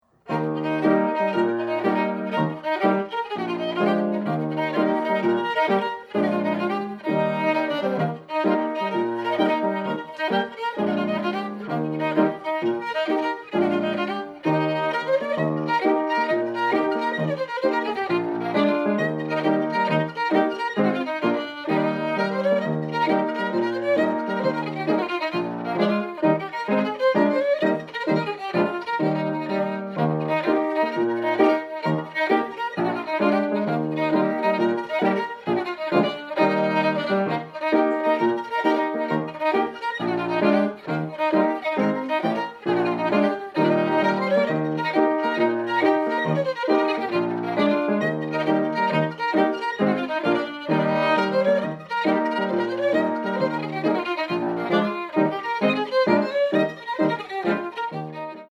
strathspey